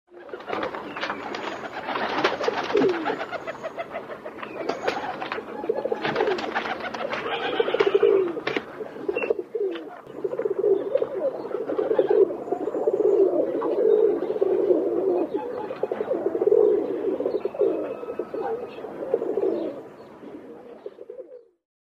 Звуки голубей
Мелодичное пение голубя